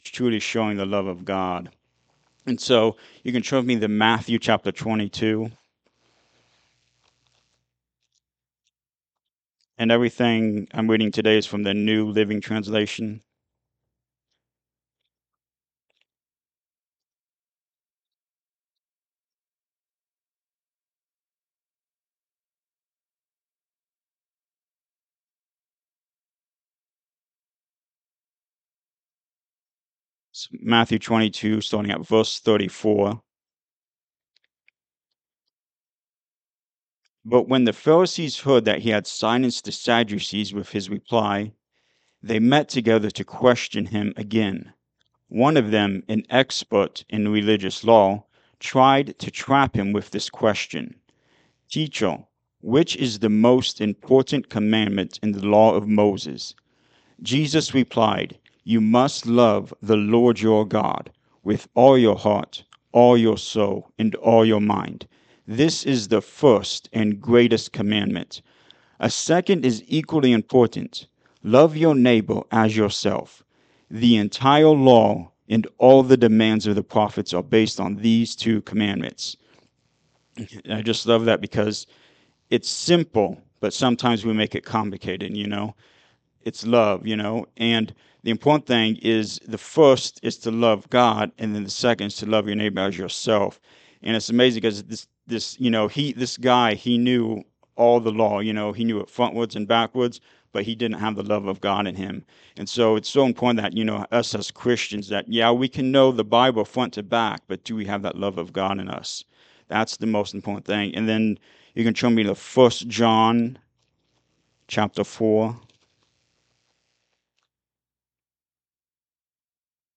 Service Type: Sunday Morning Service
Sunday-Sermon-for-January-4-2026.mp3